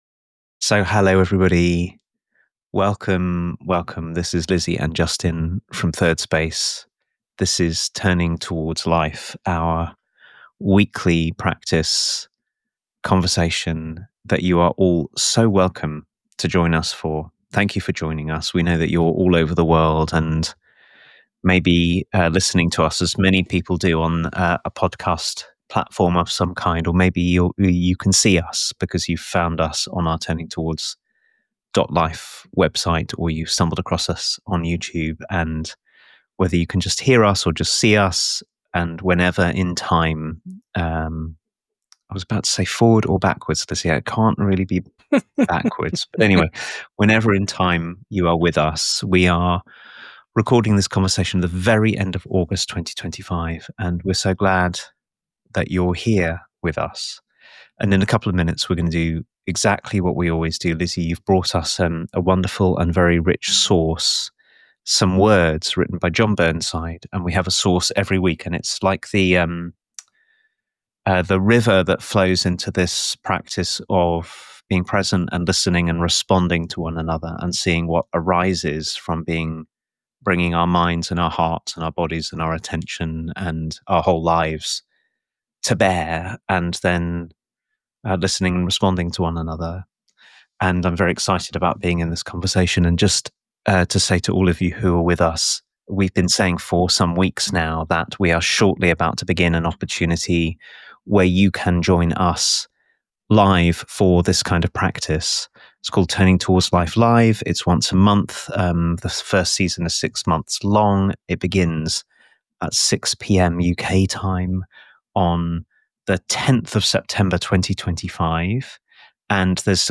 What happens when we discover that our bodies know a kinship with the earth our minds may have forgotten? Could belonging be less about finding our place and more about recognising we never left it? This week’s conversation is hosted